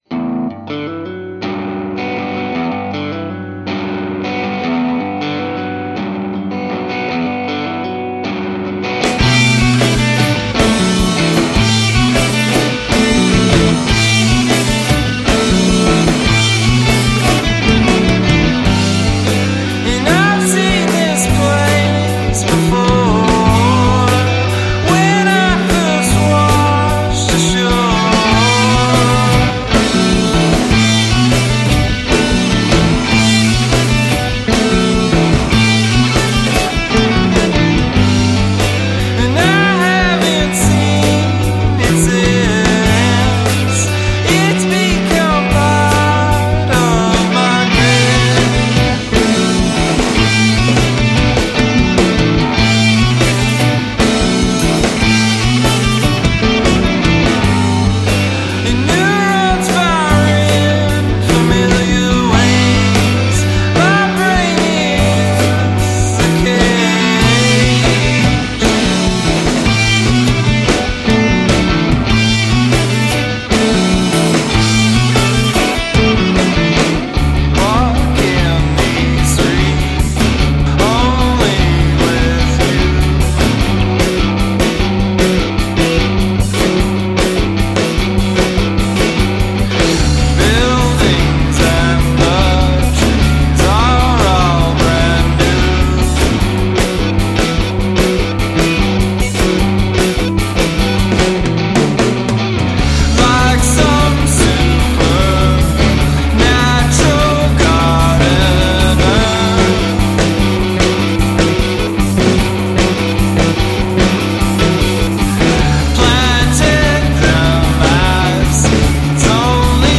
guitar
drums
bass+vocals